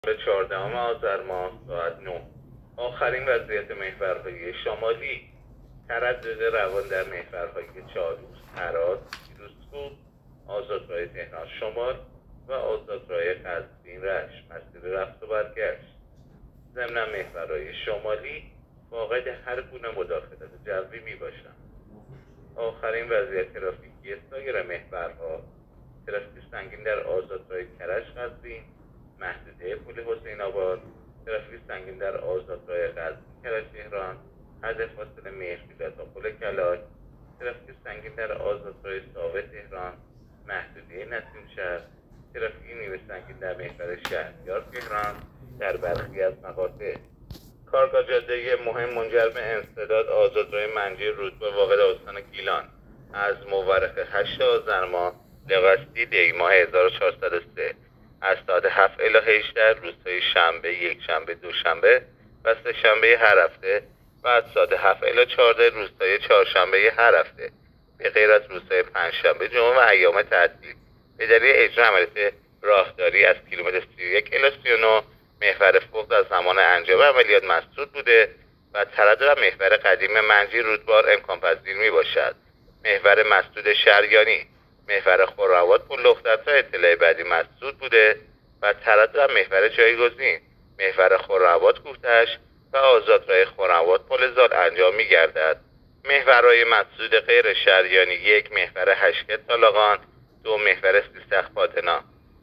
گزارش رادیو اینترنتی از آخرین وضعیت ترافیکی جاده‌ها تا ساعت ۹ چهاردهم آذر؛